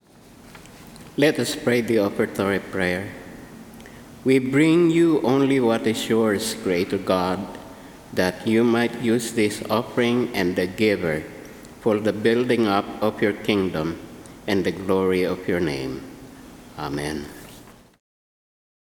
Offertory Prayer